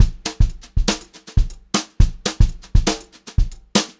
120HRBEAT2-R.wav